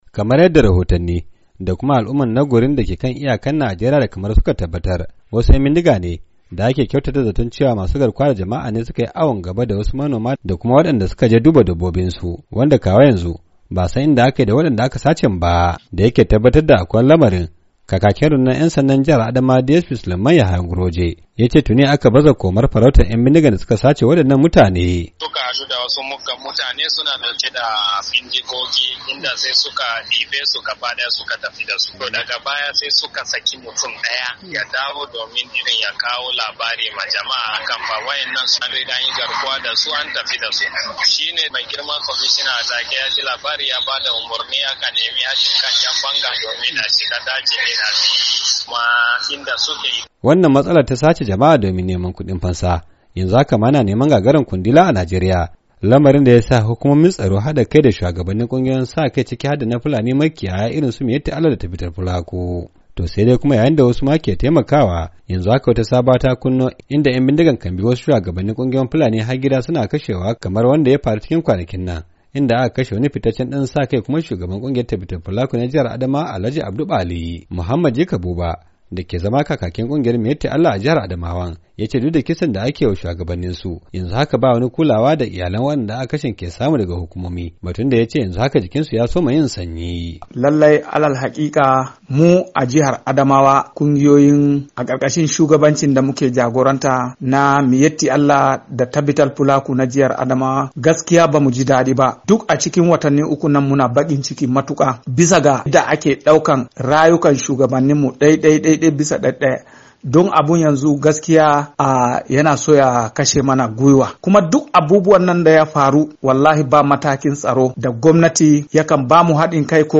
Ga cikakken rahoto daga wakilin Muryar Amurka